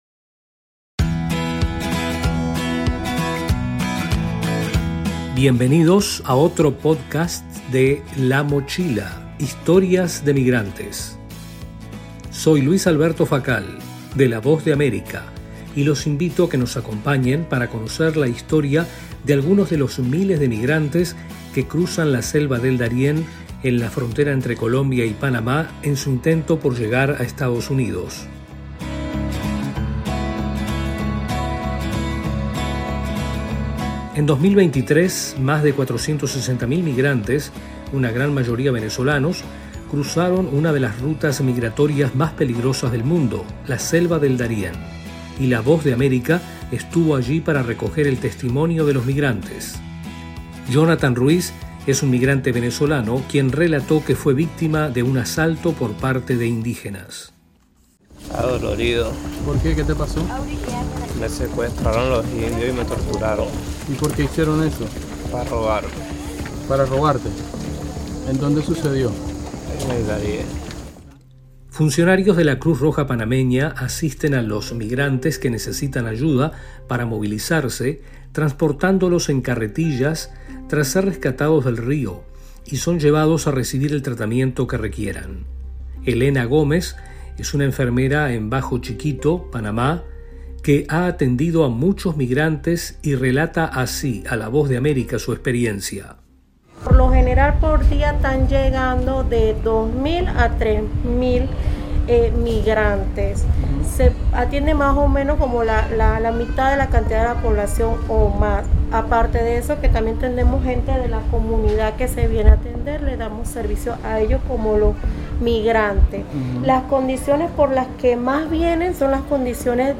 Un flujo incesante de migrantes, principalmente venezolanos, cruza la peligrosa selva del Darién en la frontera colombo-panameña en camino a Estados Unidos. Aquí les presentamos los testimonios de algunos venezolanos que se atrevieron a la arriesgada travesía.